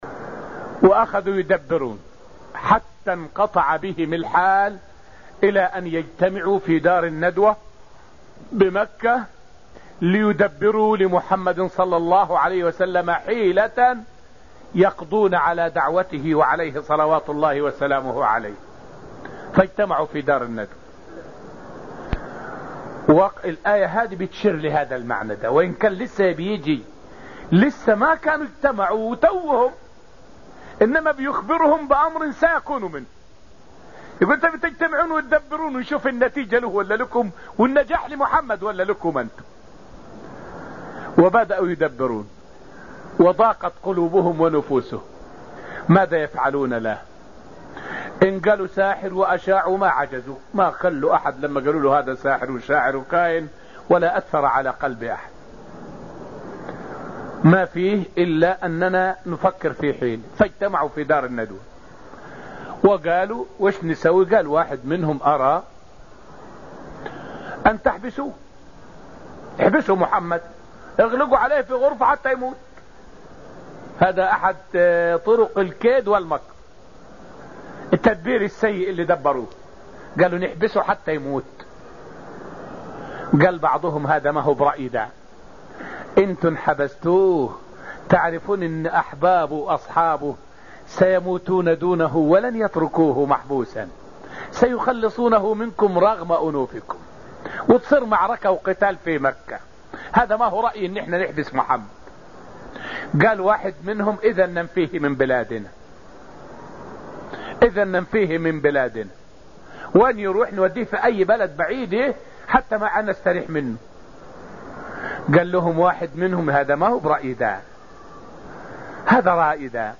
فائدة من الدرس السابع من دروس تفسير سورة الطور والتي ألقيت في المسجد النبوي الشريف حول مؤامرة قريش لاغتيال النبي صلى الله عليه وسلم.